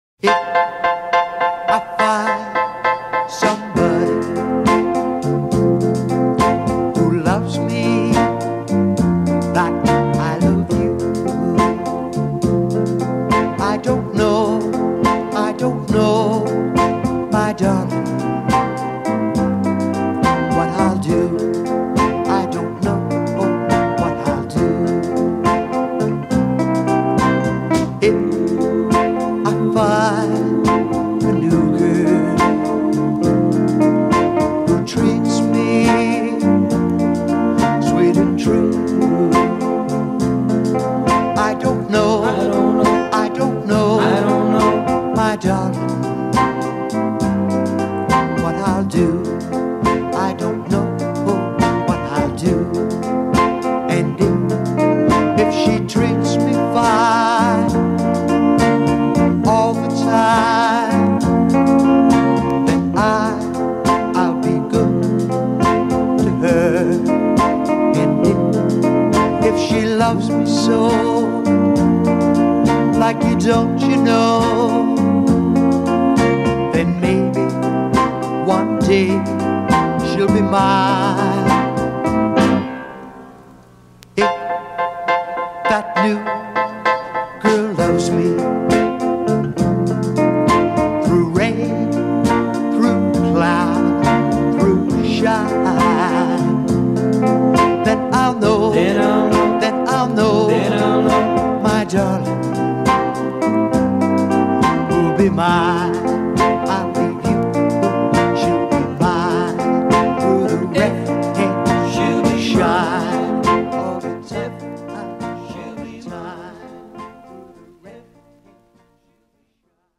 Жанр: Rock, Pop
Стиль: Rock & Roll